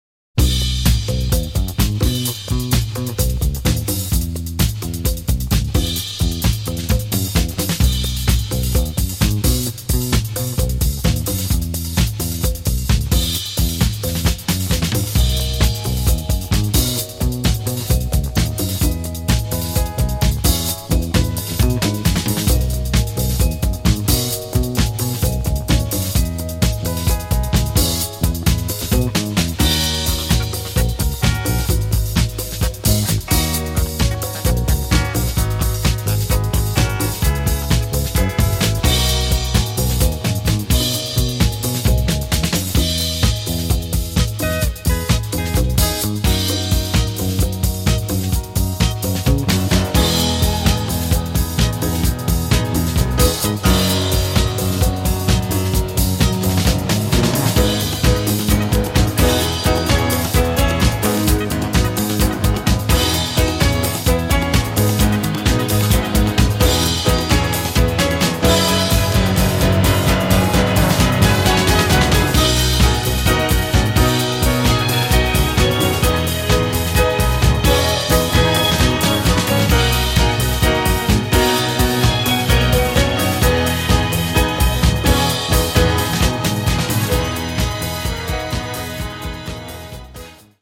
4 monstruous tracks straight from the basement